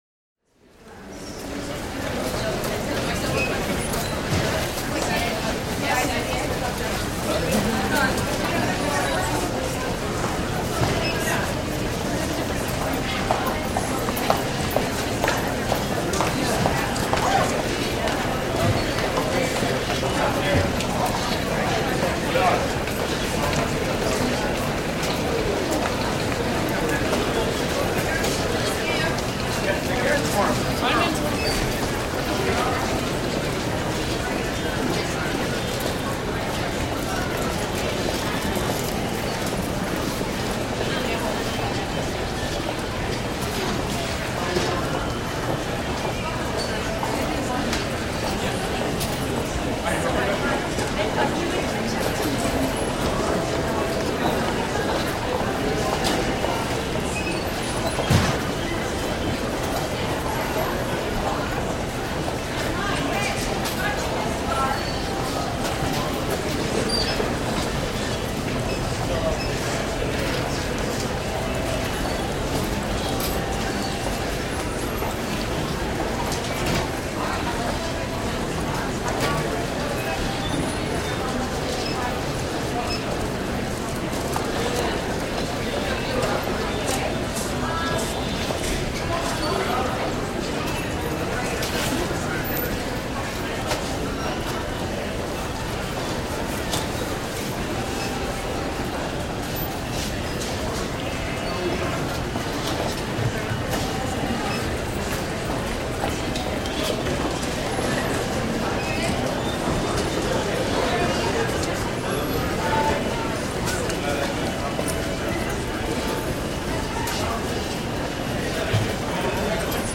Звуки автовокзала
Автобус подъехал, люди начали грузить вещи